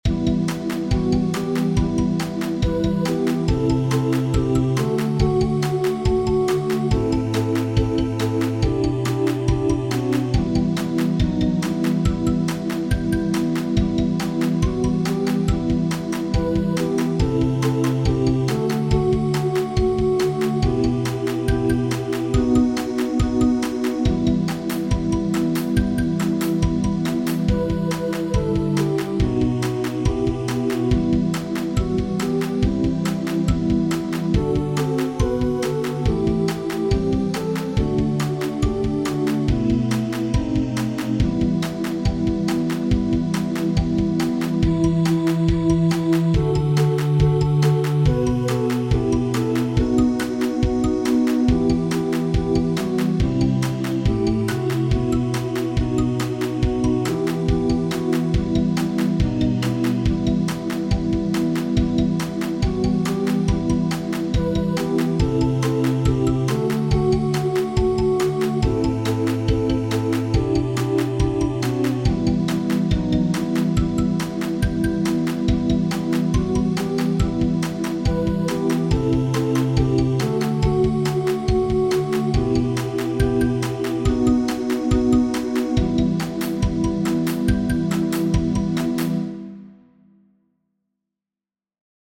• Catégorie : Chants de Méditation